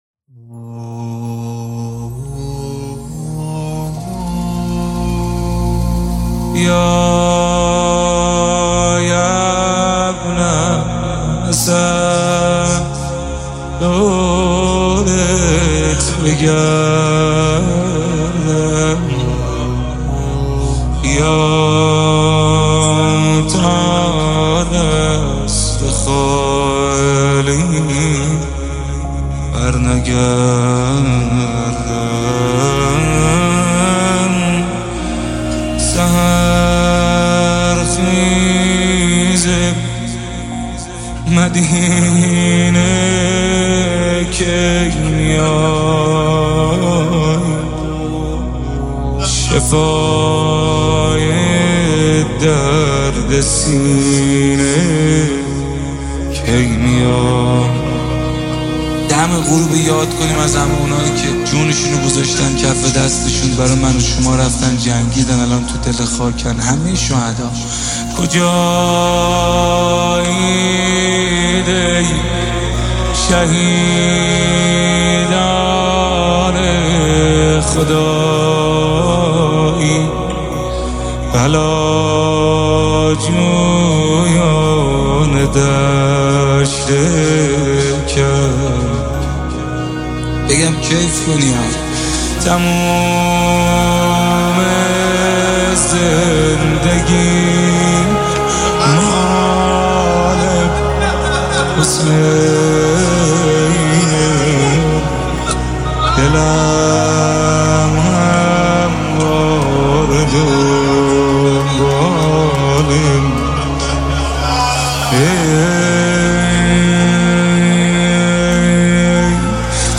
نماهنگ و مناجات مهدوی
با نوای دلنشین